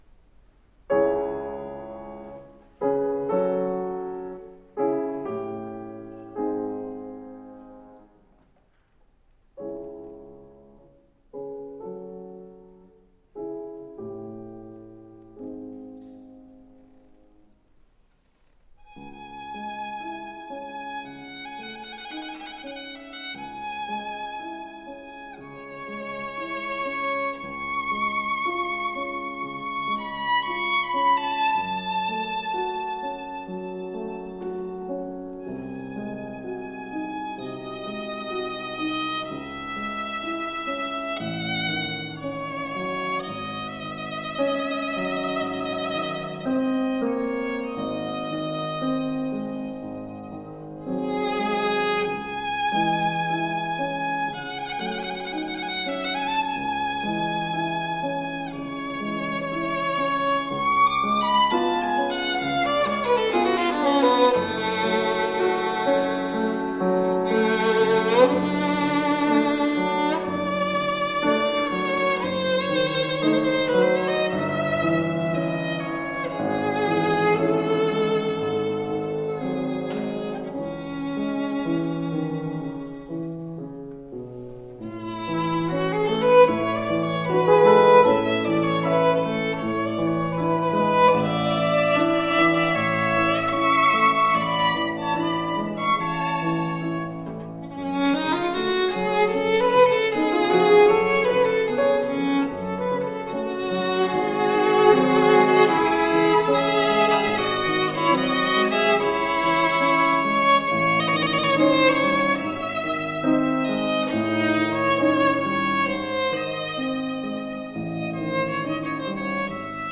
Emerging Artists Concert recordings - June 30, 2015
Frédéric Chopin (1810-1849) (arr. Nathan Milstein)
violin
piano